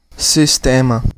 Ääntäminen
IPA: /siʃˈtemɐ/